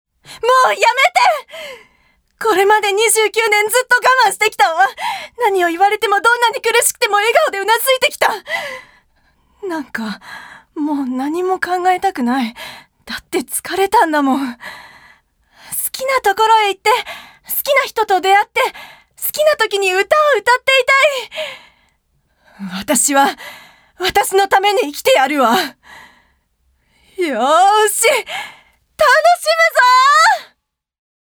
ボイスサンプル、その他
セリフ２